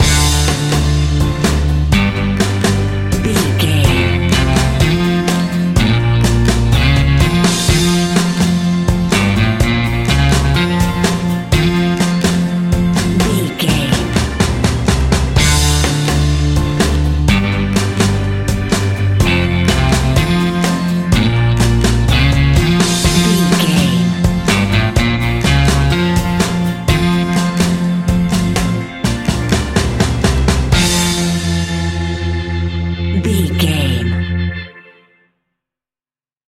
Ionian/Major
60s
fun
energetic
uplifting
cheesy
acoustic guitars
drums
bass guitar
electric guitar
piano
electric piano
organ